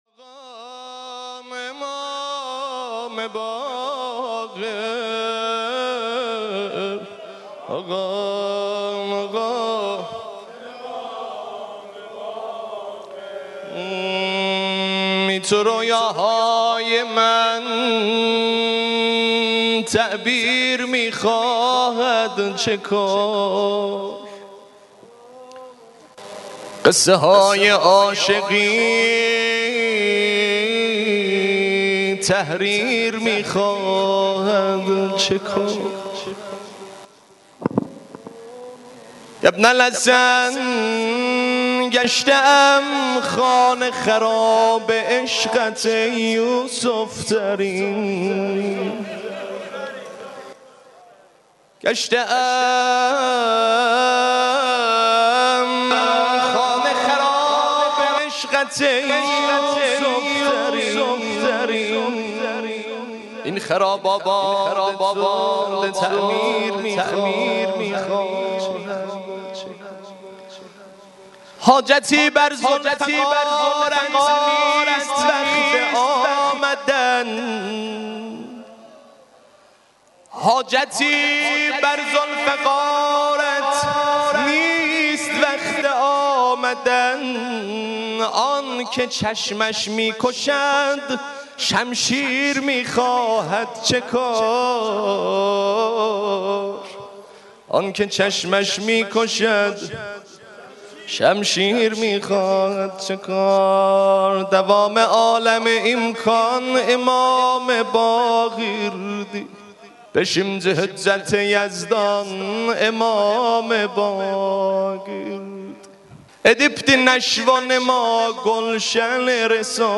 عزاداری در بازار تهران